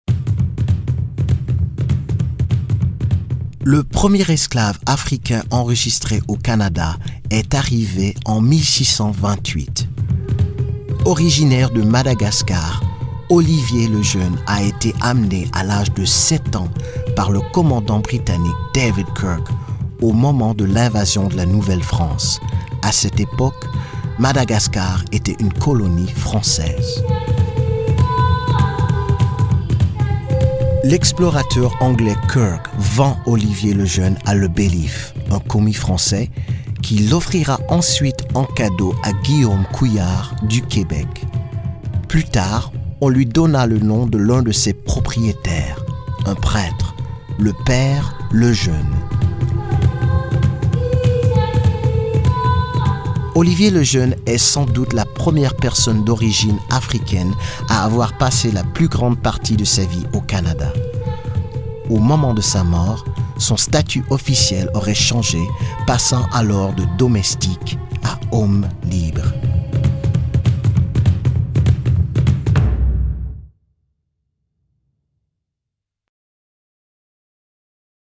Narrateur: